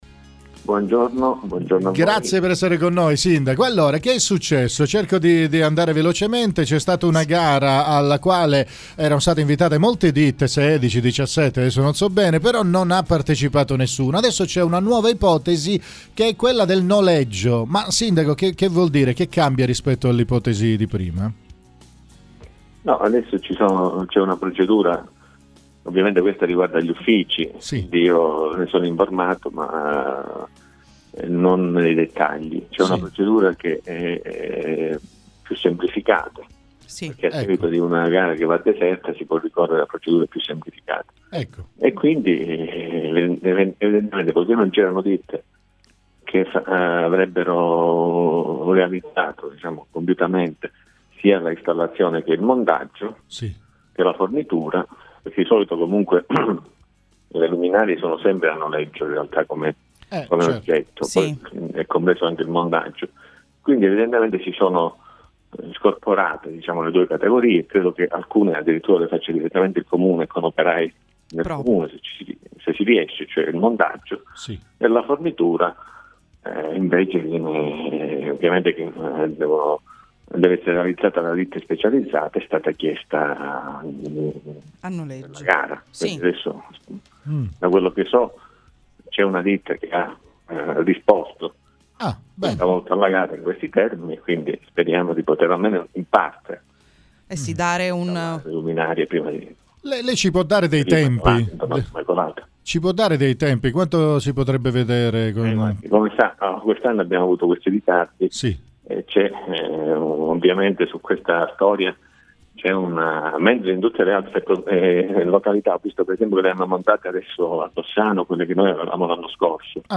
Ad annunciarlo è stato il primo cittadino in persona oggi ai microfoni di RLB RadioAttiva.
ASCOLTA LE DICHIARAZIONI DI MARIO OCCHIUTO